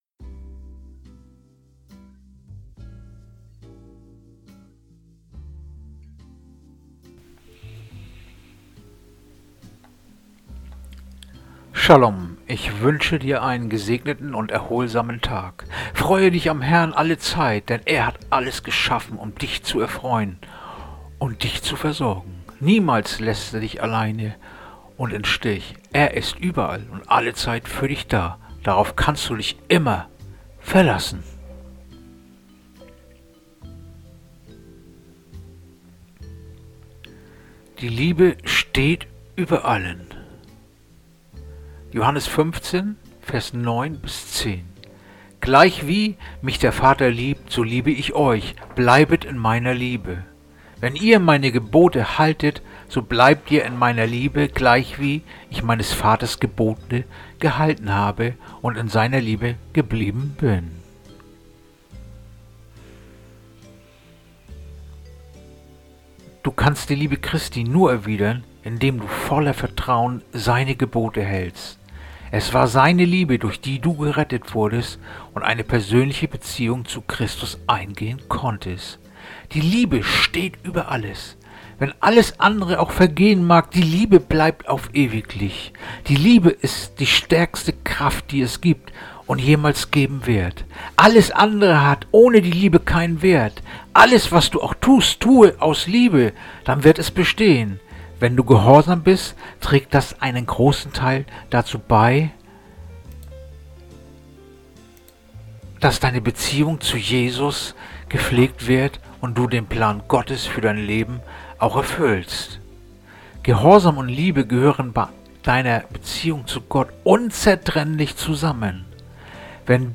Andacht-vom-02-Dezember-Johannes-15-9-10